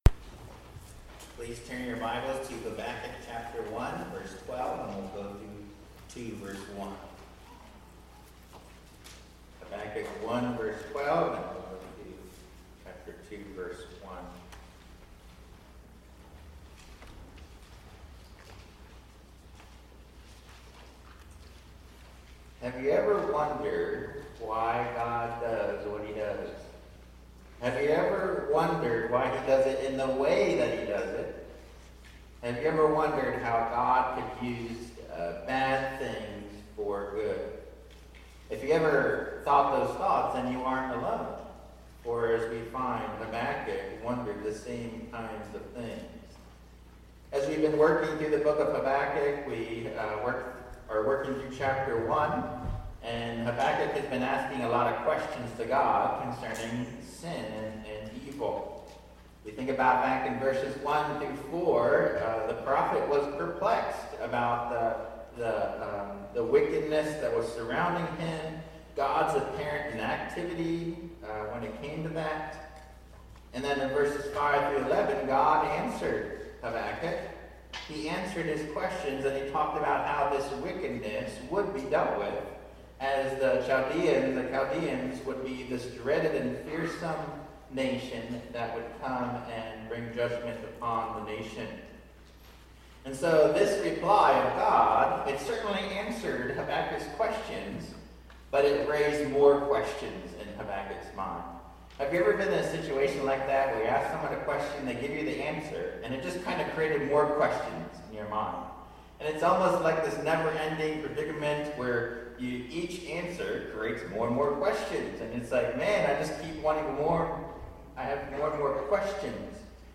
Bible Text: Habakkuk 1:12-2:1 | Preacher: